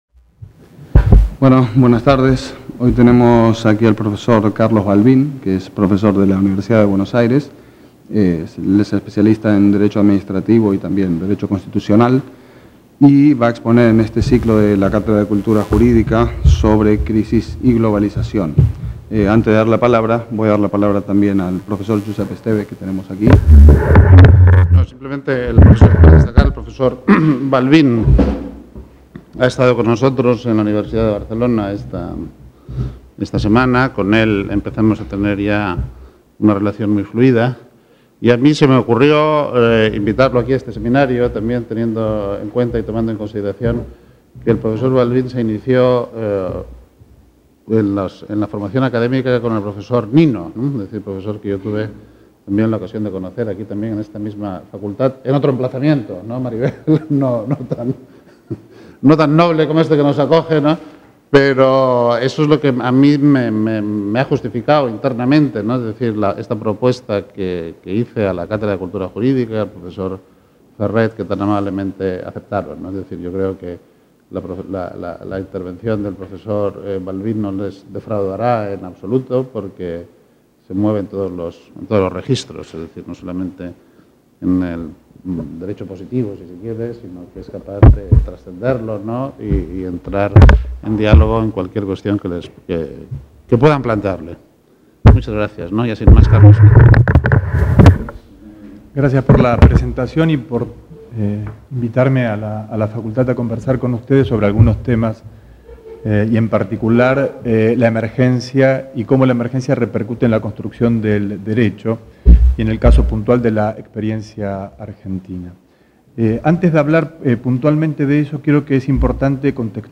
Seminari